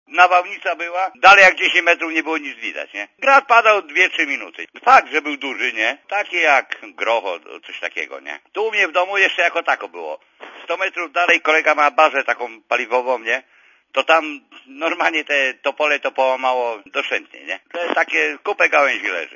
Dla Radia ZET mówi